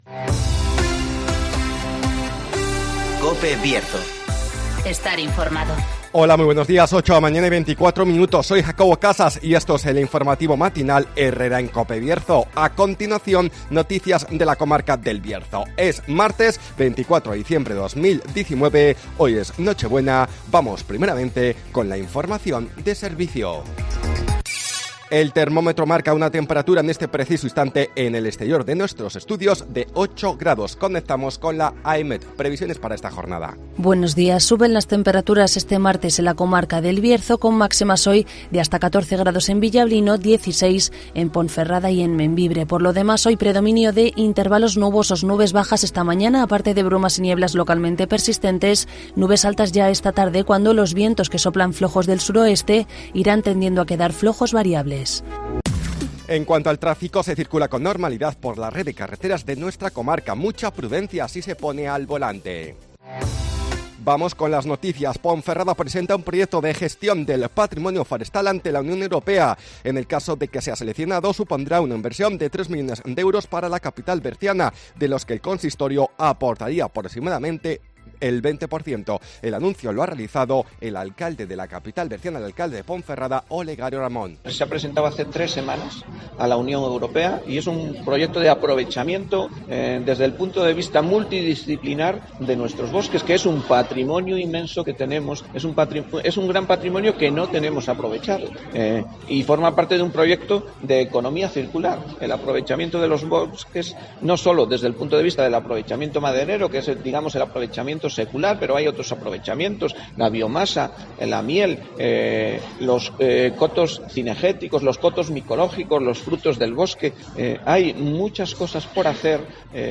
INFORMATIVOS BIERZO
-Conocemos las noticias de las últimas horas de nuestra comarca, con las voces de los protagonistas